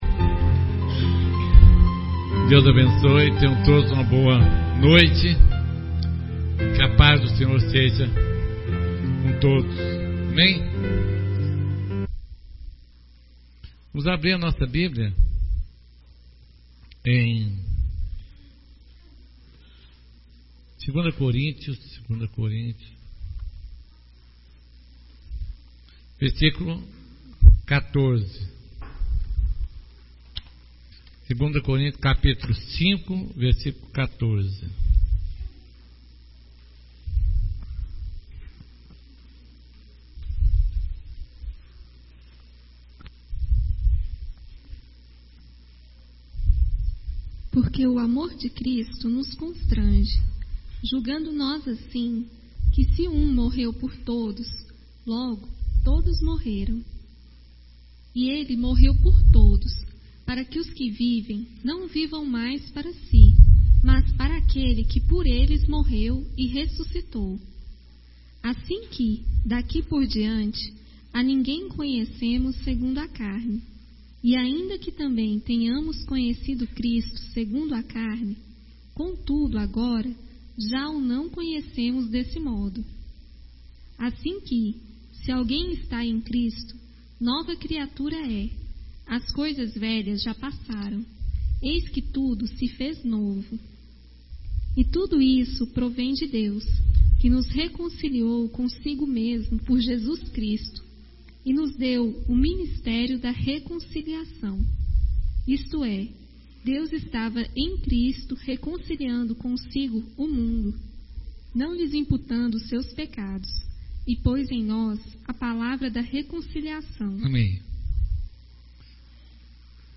Resumo reunião de quarta 27.10.10